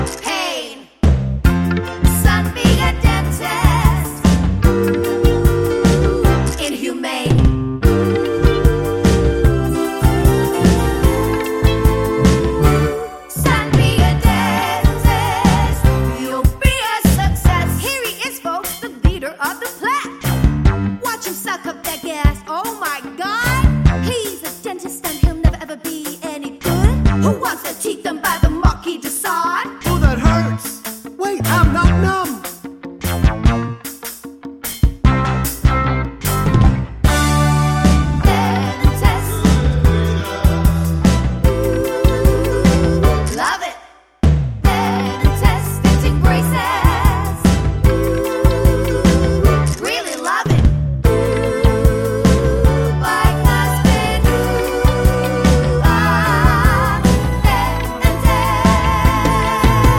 No Backing Vocals Soundtracks 2:28 Buy £1.50